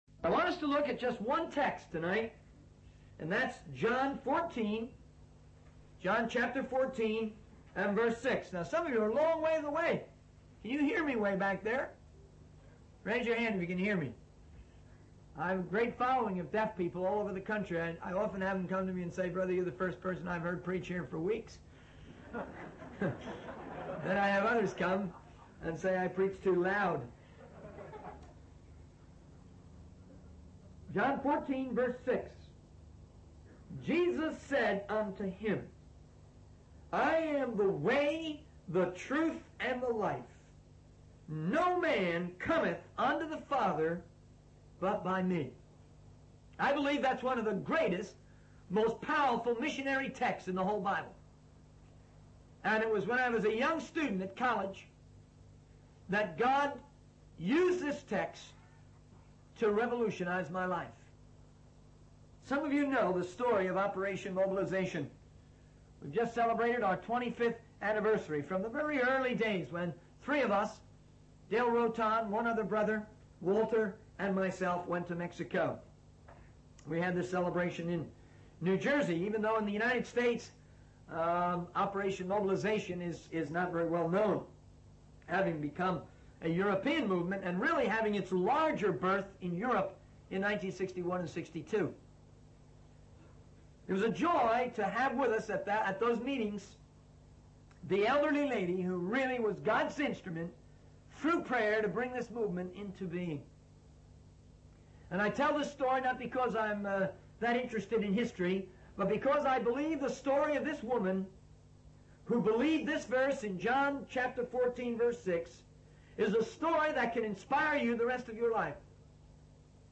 In this sermon, the speaker emphasizes the importance of believing in Jesus as the only way to the Father.